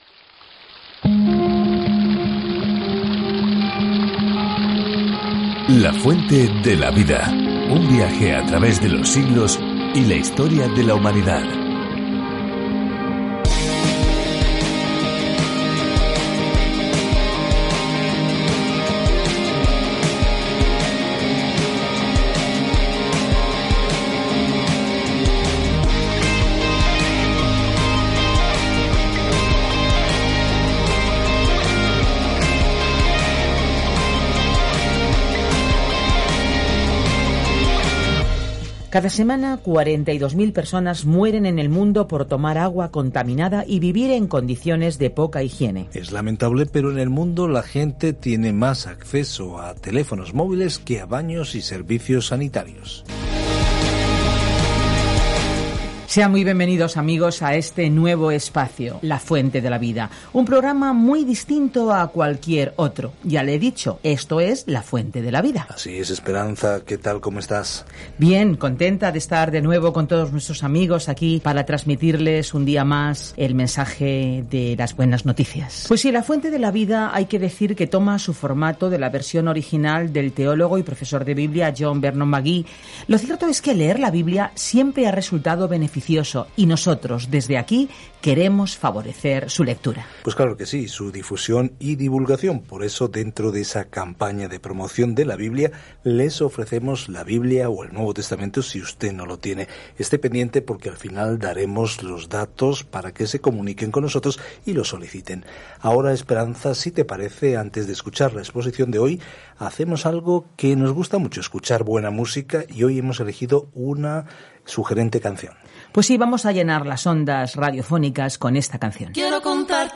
Viaje diariamente a través de Ester mientras escucha el estudio de audio y lee versículos seleccionados de la palabra de Dios.